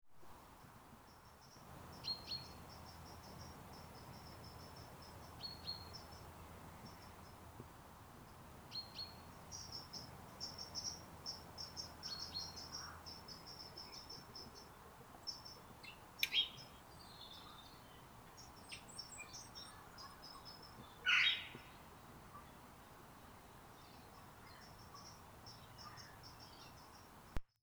NatureDay.wav